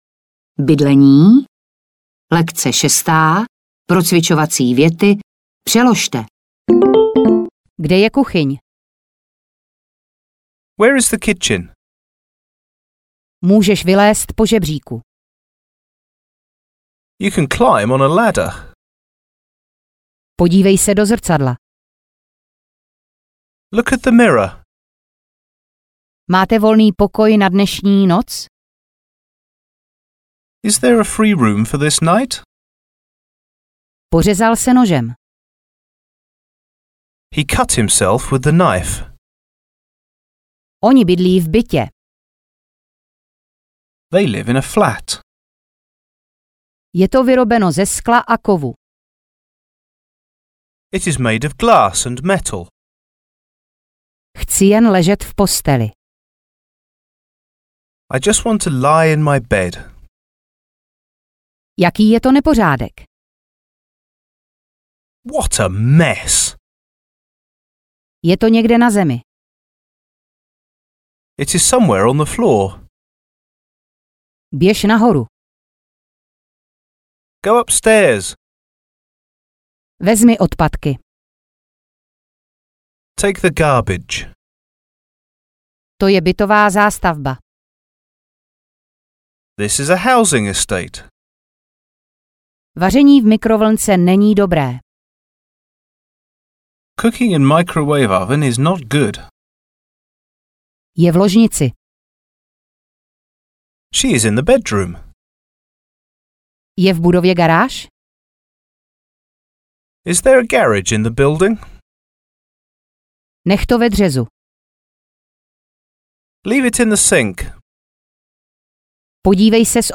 Komunikační okruhy audiokniha
Ukázka z knihy